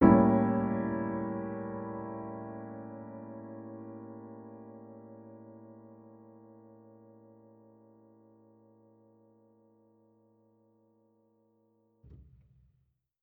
Index of /musicradar/jazz-keys-samples/Chord Hits/Acoustic Piano 2
JK_AcPiano2_Chord-A7b9.wav